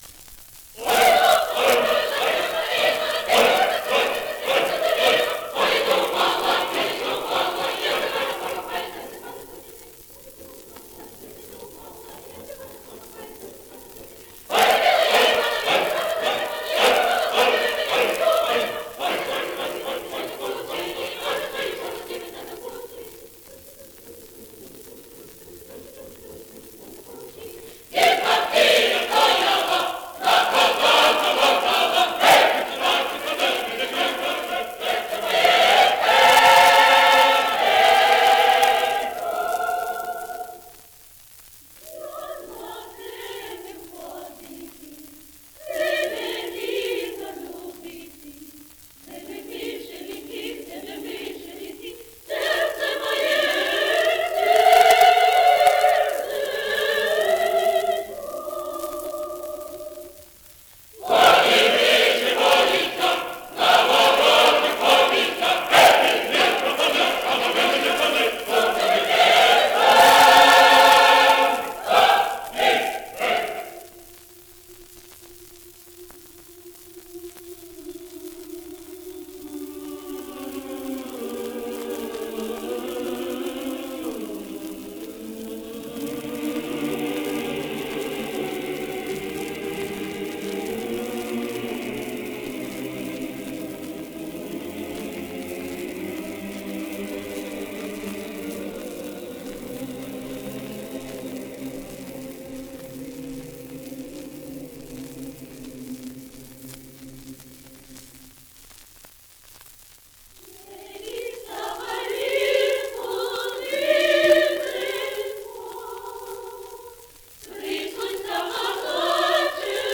Состав хора в этой поездке насчитывал 100 человек — 60 взрослых (мужчин и женщин) и 40 детей (мальчиков и девочек).
Исполнители: Хор Государственной академической капеллы
Дирижёр — Михаил Климов
Записи сделаны 9 и 14 января 1928 года в Берлине в студии Electrola.